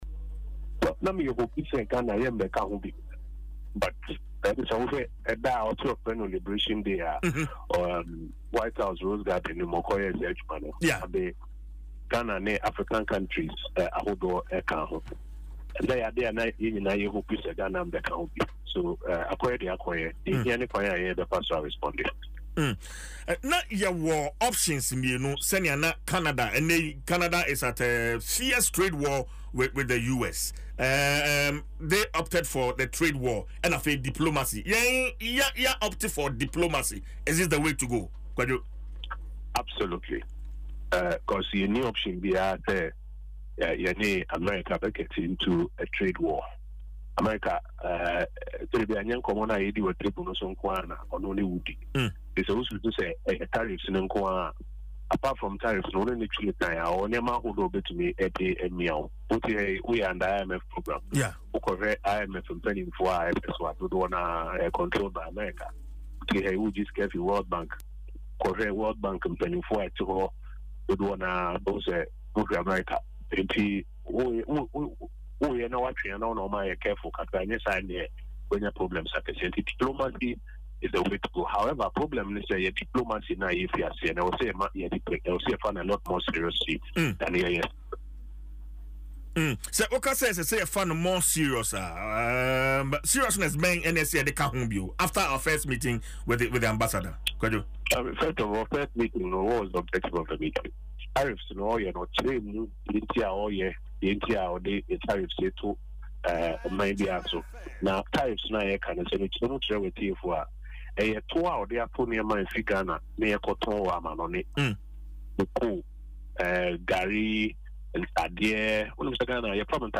In an interview on Adom FM’s Dwaso Nsem , Oppong Nkrumah acknowledged the global impact of the tariffs but emphasised that Ghana’s response will make the difference.